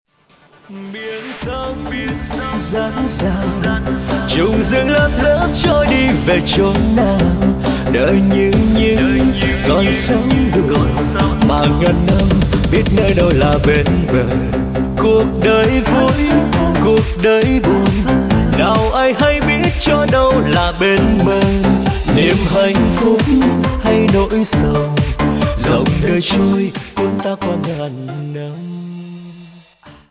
Nhạc chờ hay